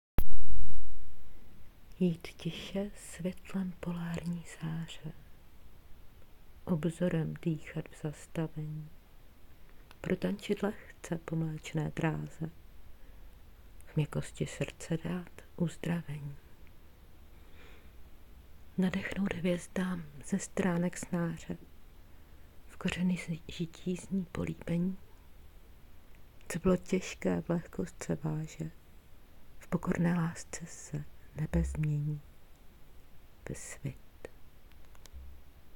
Tvůj hlas jakoby přicházel z jiného světa - léčí duši.
Bylo to, jako bys mi něžně šeptala do ucha svou Opravdovost.